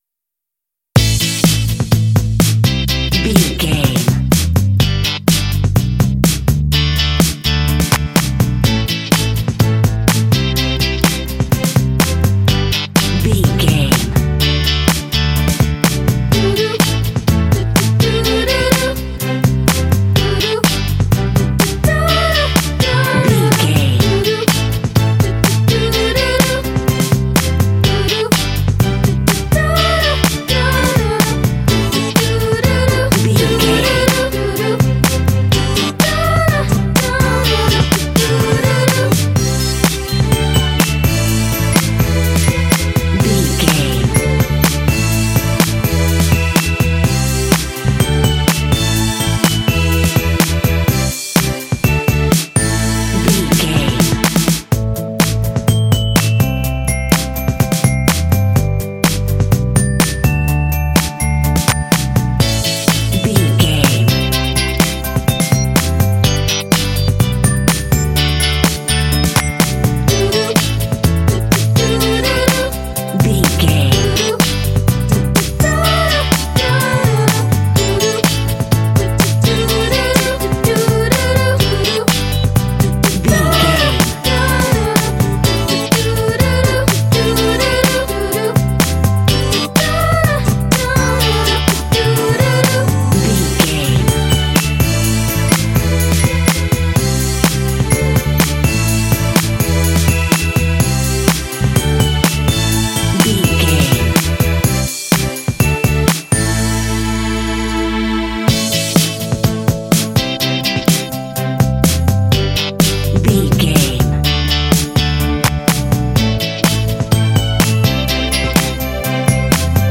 Aeolian/Minor
optimistic
happy
bright
strings
drums
bass guitar
electric guitar
pop
contemporary underscore
rock
indie